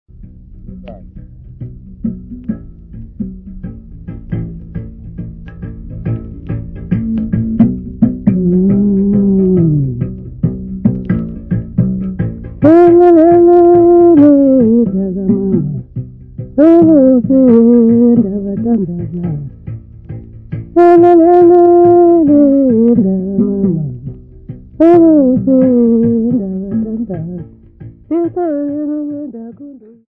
Amampondo locals
Folk music
Sacred music
Field recordings
Africa South Africa Langa, Cape Town sa
Traditional Xhosa song with singing and Uhadi